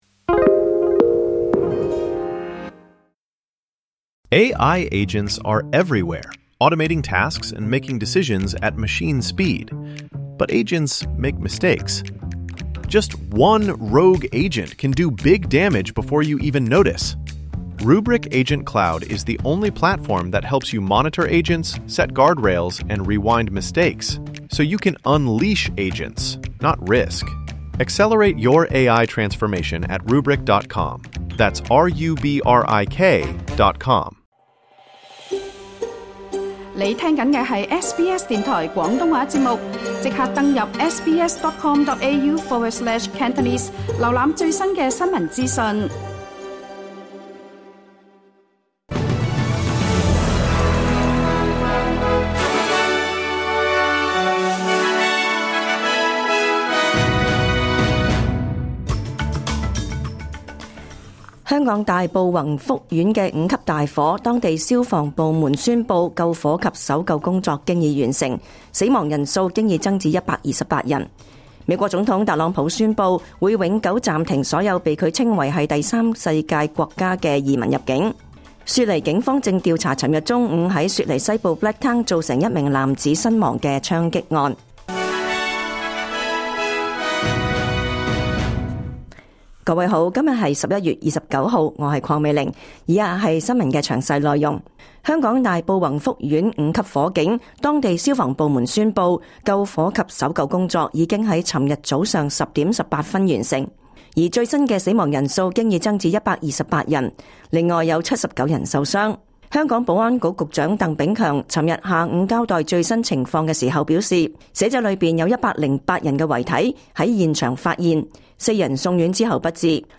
2025 年 11 月 29 日 SBS 廣東話節目詳盡早晨新聞報道。